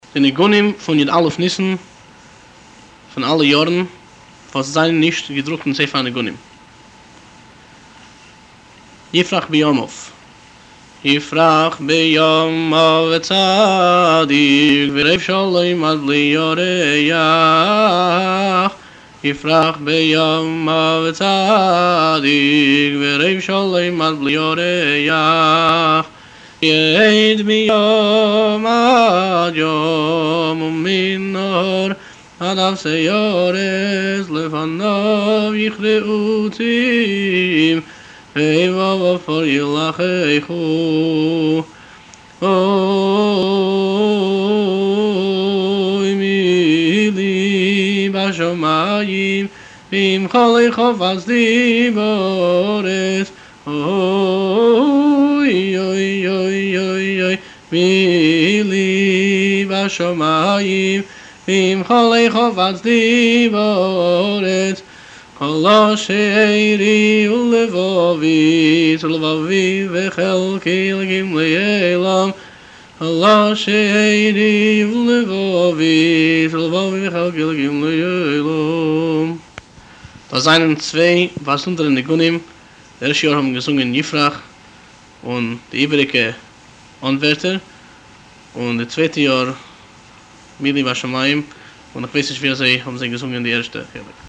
הניגון המקובל כ'יפרח בימיו'